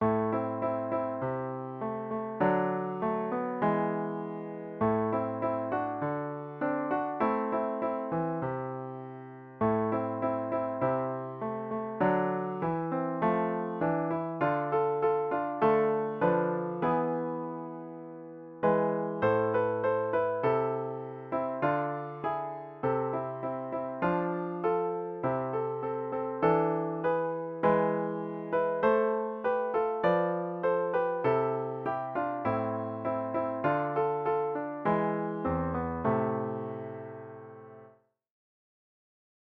sound | piano 2